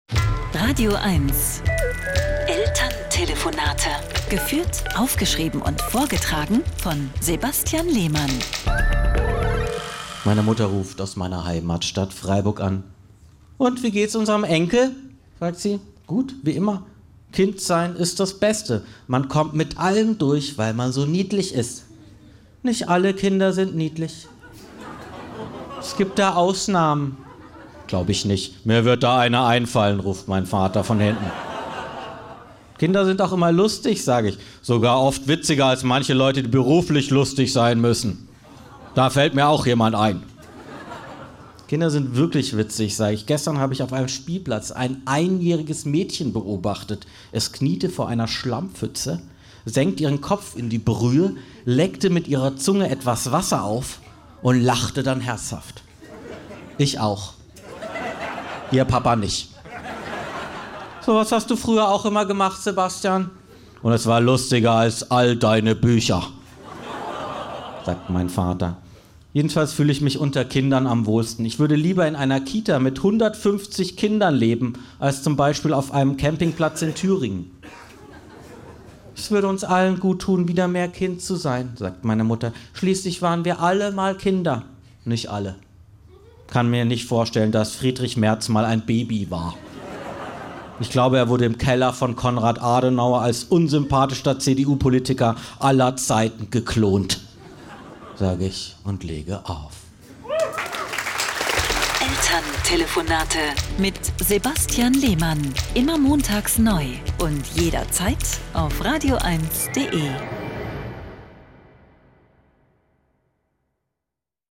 Deswegen muss er oft mit seinen Eltern in der badischen Provinz telefonieren.
Comedy